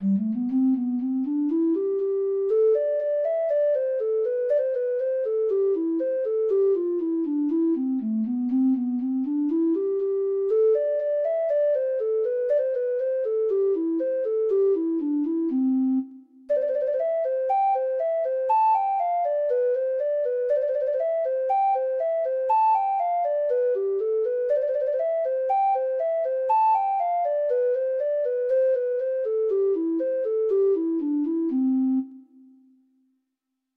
Free Sheet music for Treble Clef Instrument
Traditional Music of unknown author.
Reels
Irish